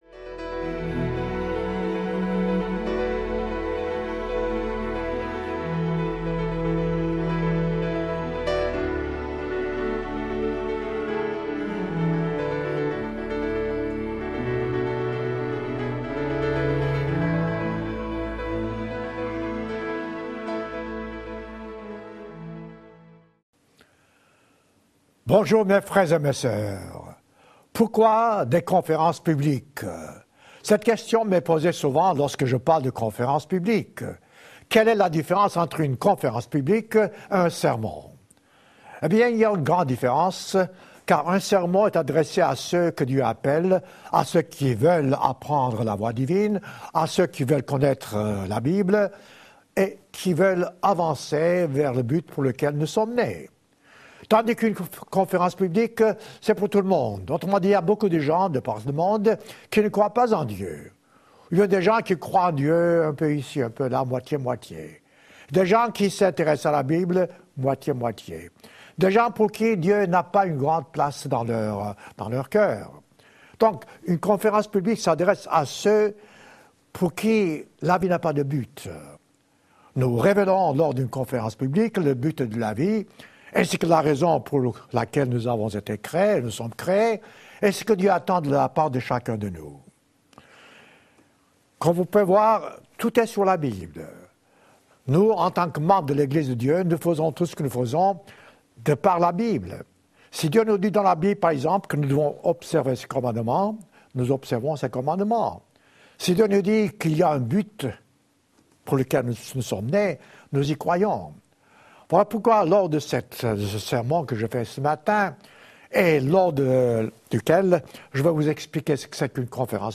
Sermon ou conférence publique